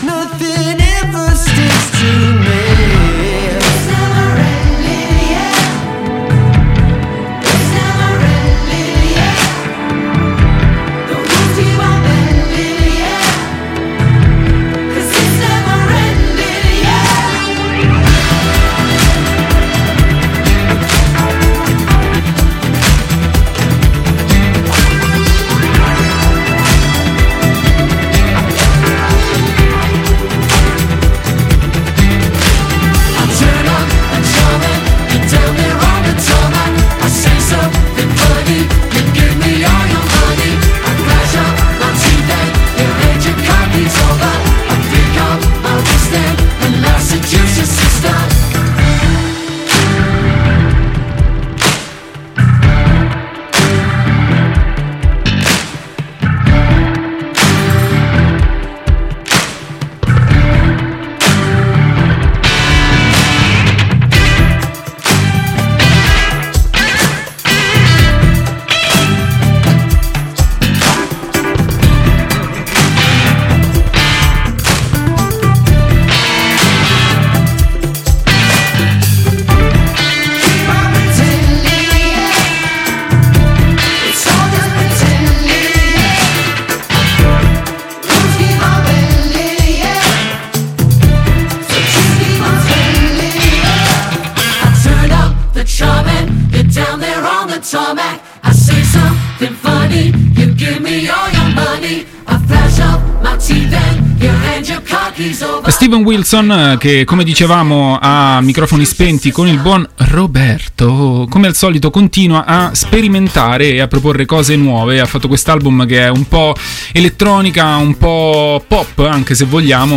Come sempre, poi, tanta musica.